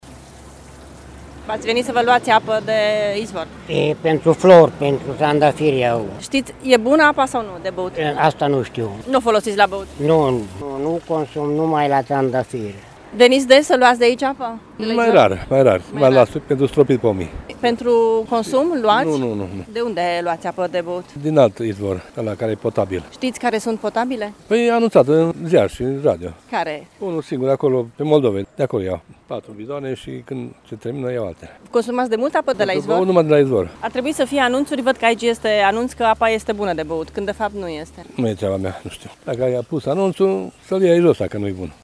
Oamenii care vin la acest izvor știu că apa nu este potabilă pentru că au aflat de la radio: